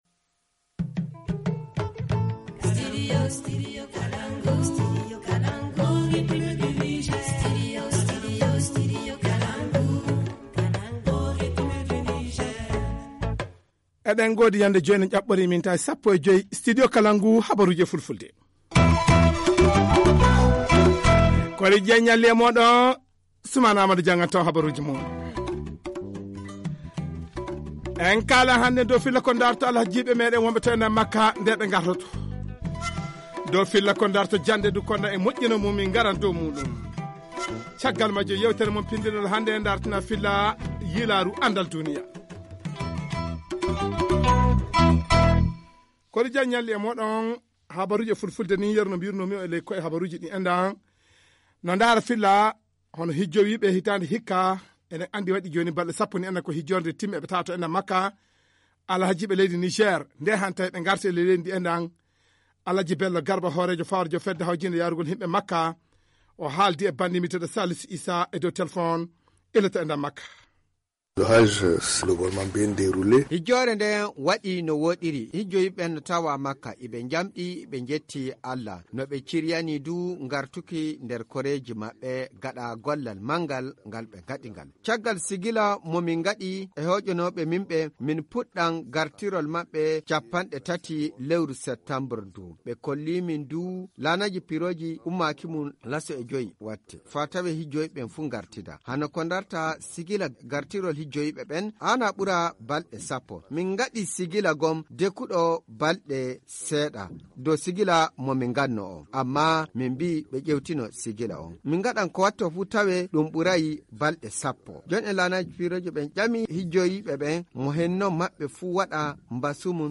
Journal du 27 septembre 2016 - Studio Kalangou - Au rythme du Niger